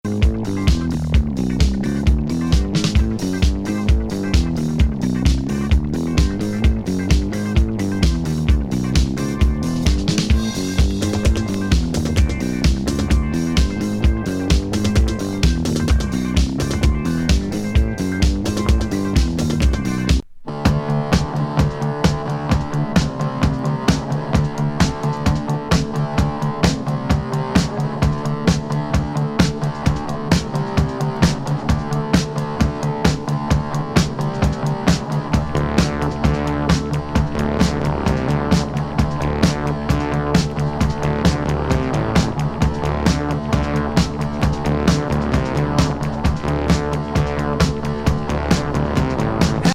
スペース・ブギー
ロッキン・ディスコ